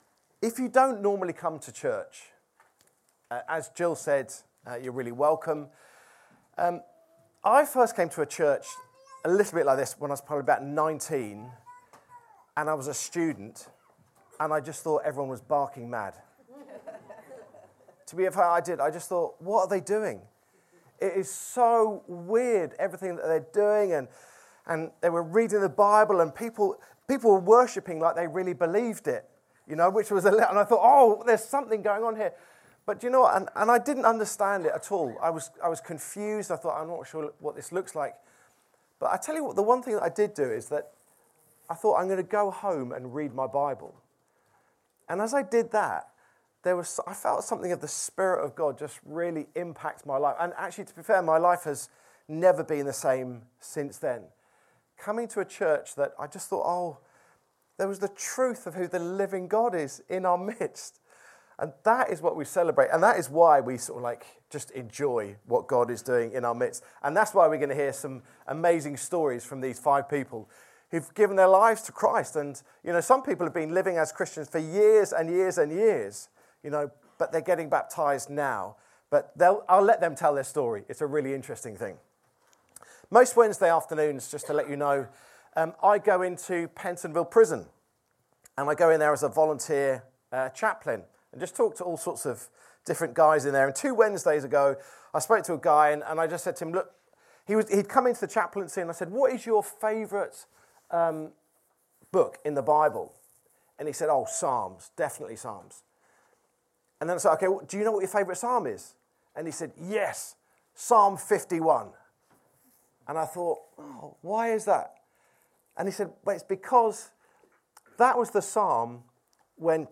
Download Easter Sunday 2026 | Sermons at Trinity Church